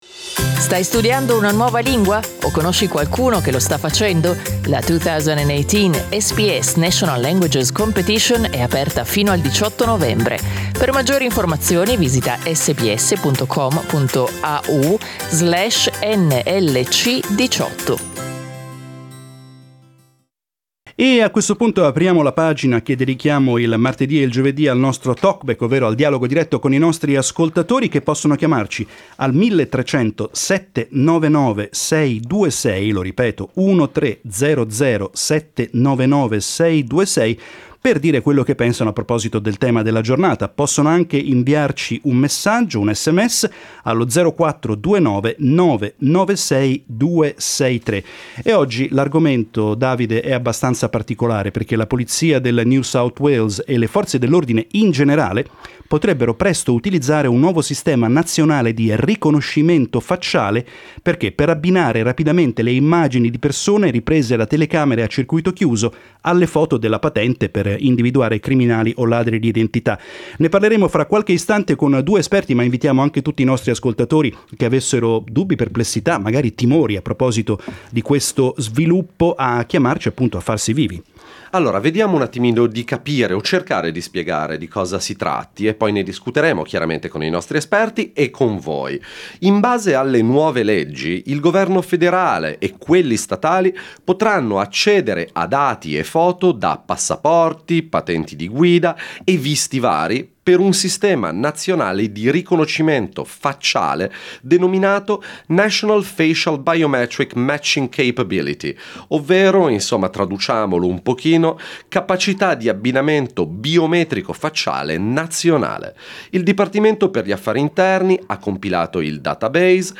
We asked this very questions to experts and listeners on today’s talk-back show.